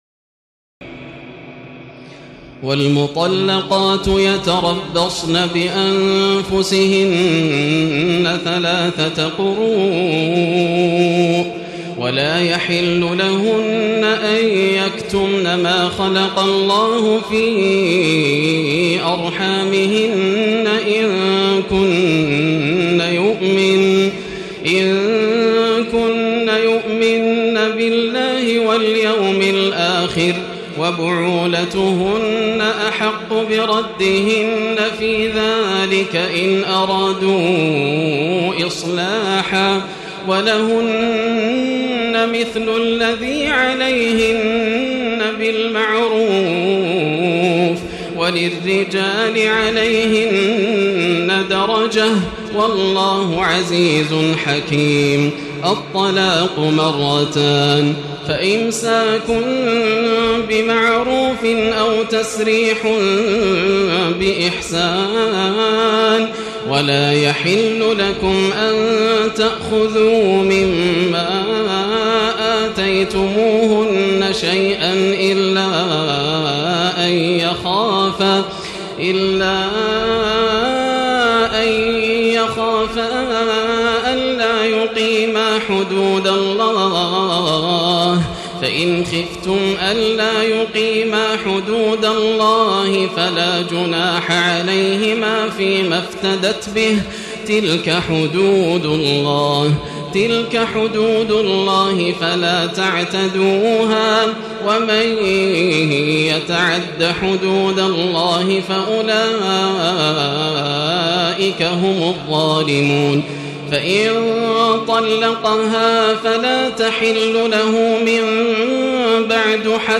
تراويح الليلة الثانية رمضان 1436هـ من سورة البقرة (228-271) Taraweeh 2 st night Ramadan 1436 H from Surah Al-Baqara > تراويح الحرم المكي عام 1436 🕋 > التراويح - تلاوات الحرمين